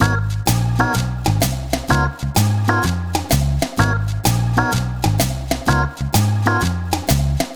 Swingerz 6 Full-G#.wav